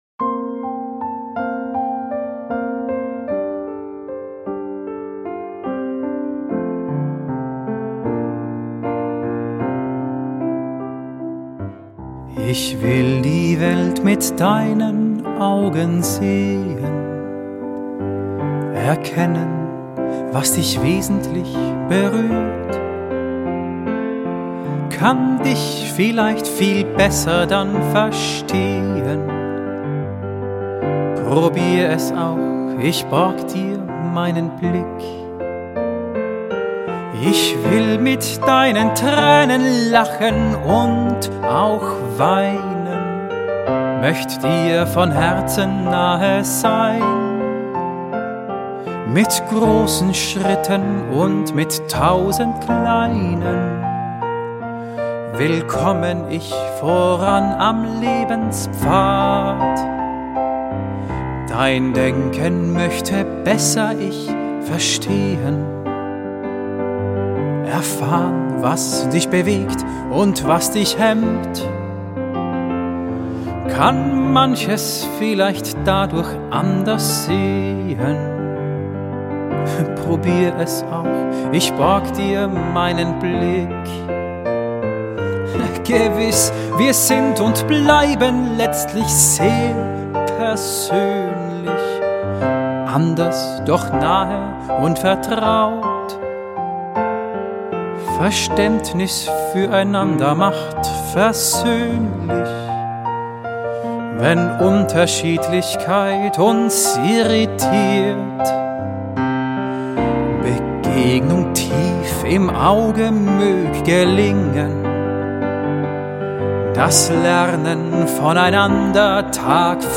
voice, piano